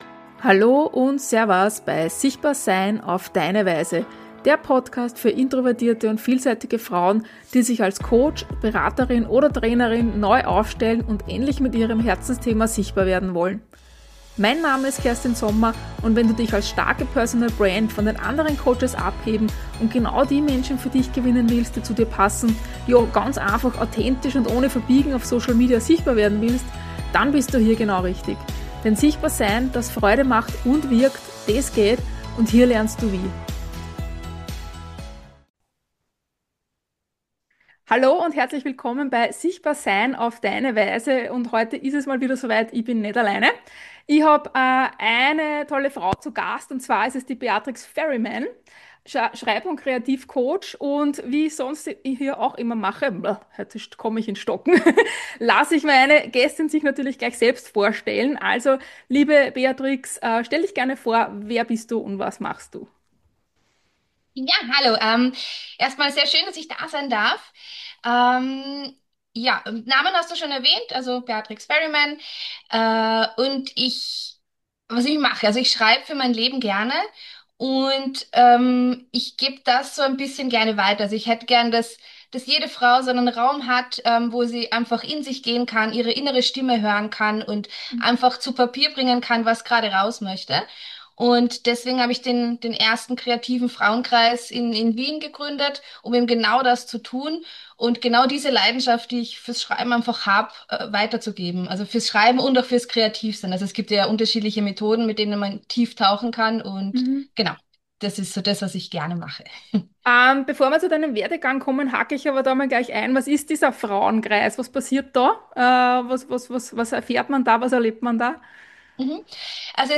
Heute gibt es wieder ein spannendes Interview auf die Ohren.